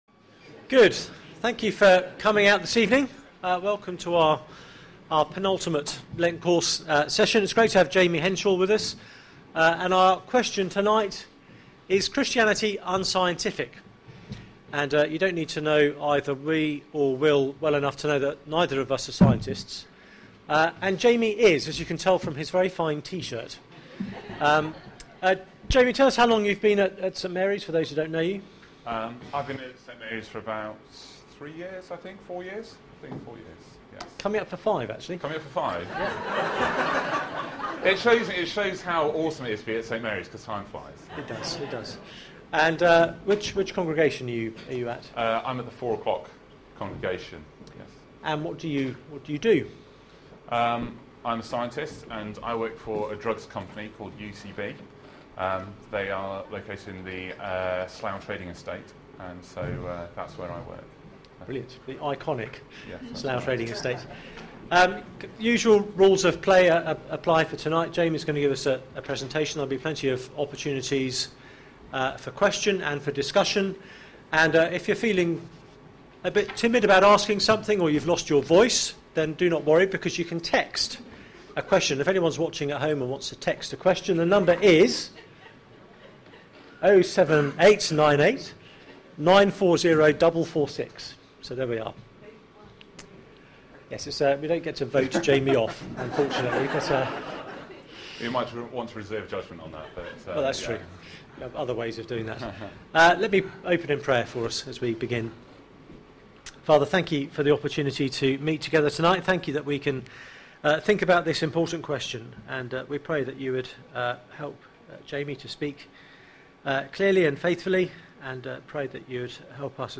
Lent Course: Isn't Christianity unscientific? Sermon Search the media library There are recordings here going back several years.